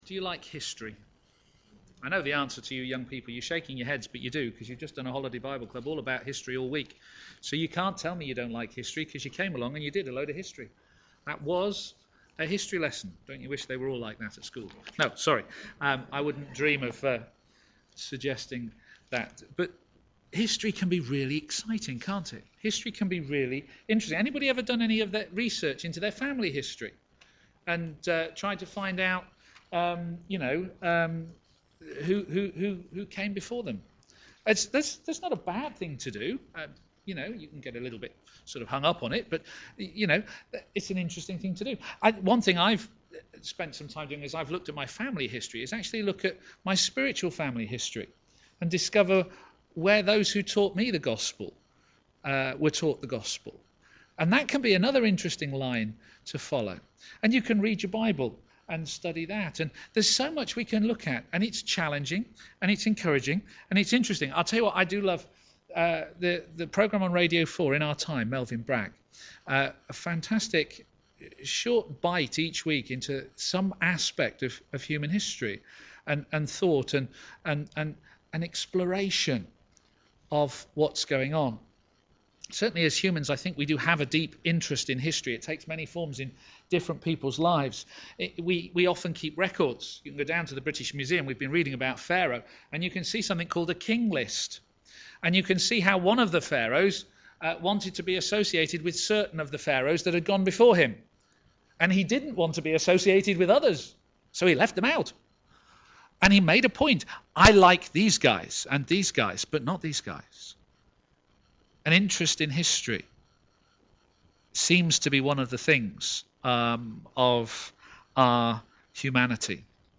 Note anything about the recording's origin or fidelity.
a.m. Service